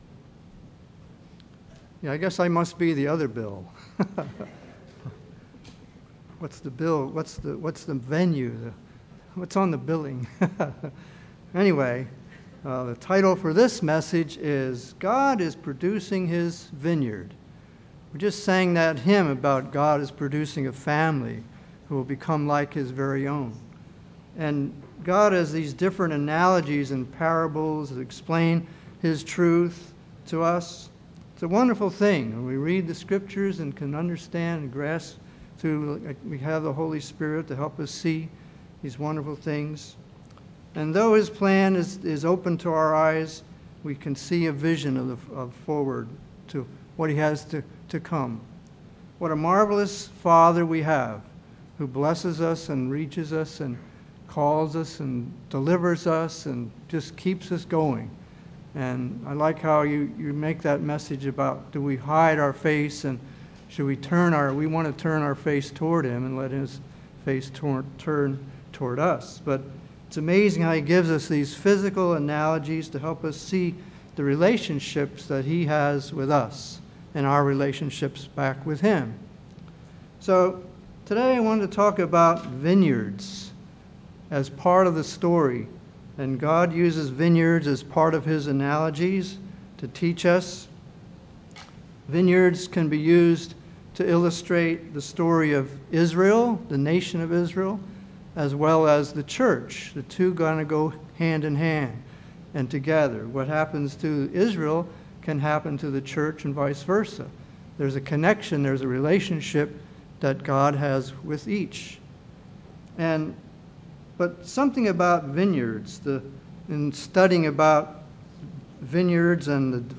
Sermons
Given in Vero Beach, FL